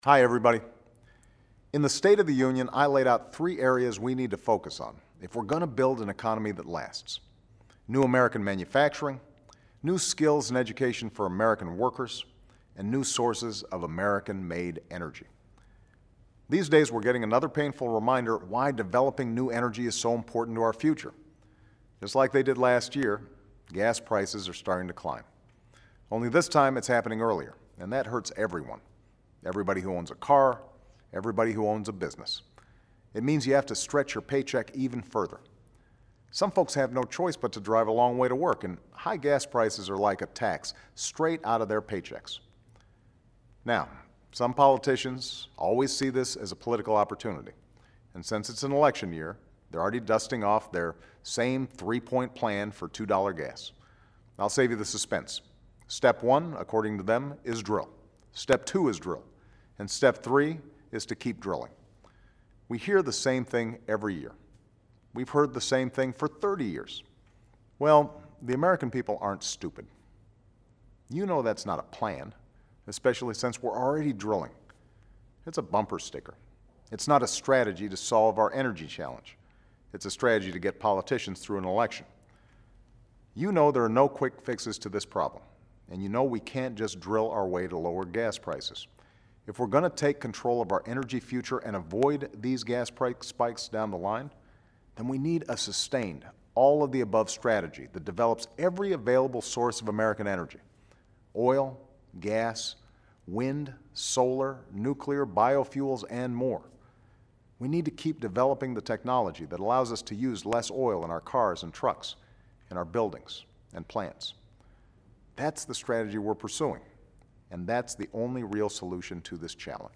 Remarks of President Barack Obama